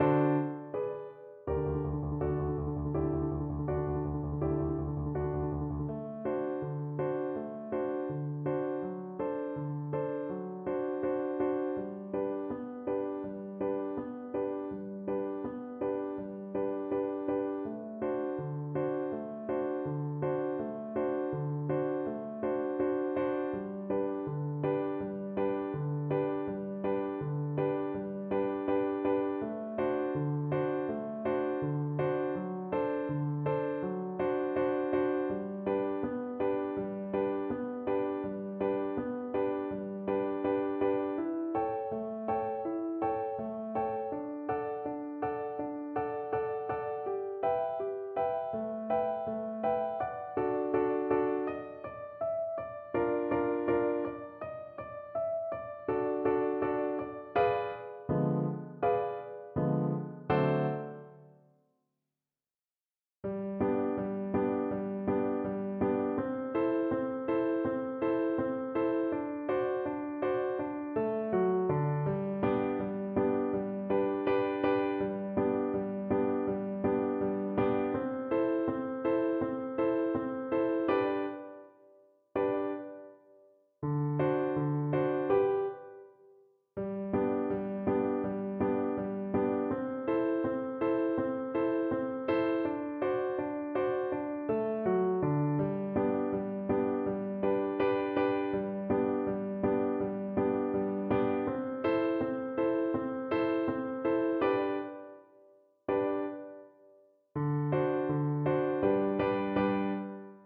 Flute
2/4 (View more 2/4 Music)
Molto allegro
Arrangement for Flute and Piano
G major (Sounding Pitch) (View more G major Music for Flute )
Classical (View more Classical Flute Music)
Polkas for Flute